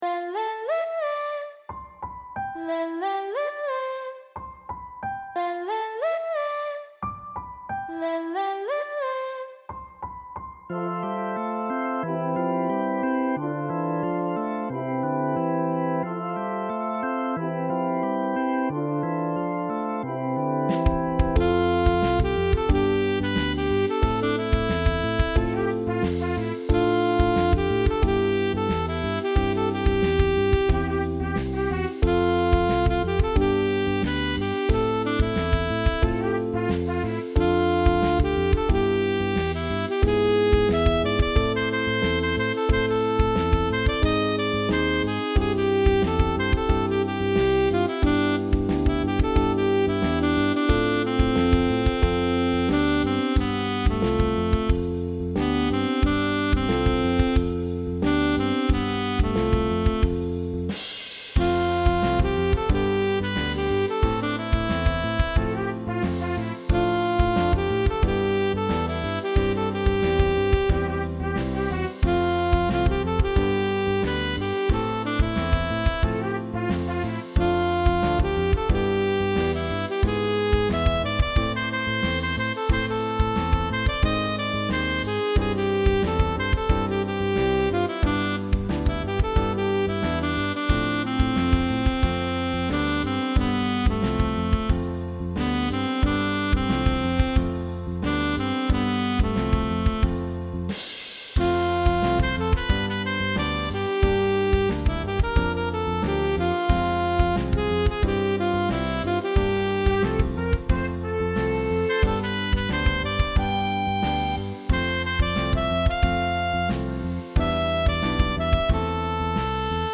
UrbanBallad